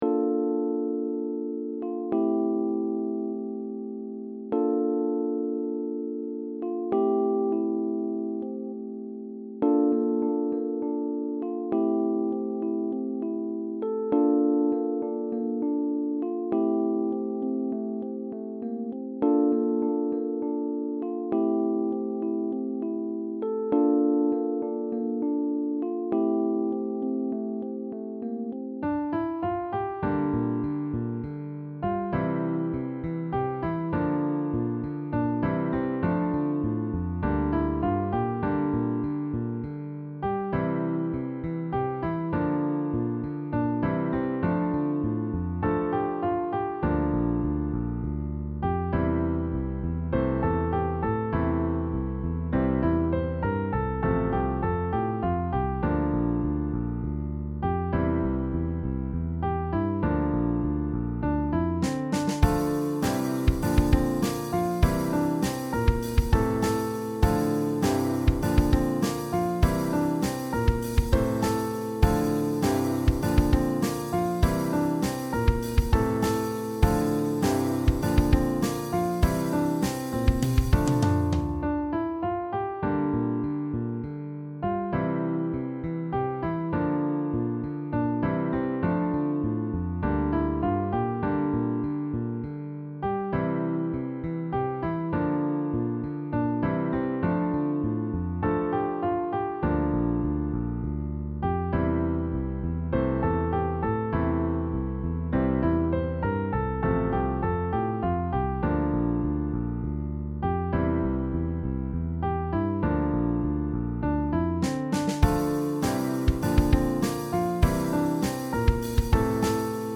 DeepBallad